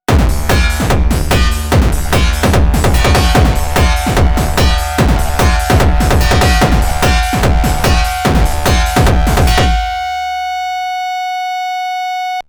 That would be the sound of a pair of laser chopsticks hitting some tinfoil-wrapped nuclear barbecue.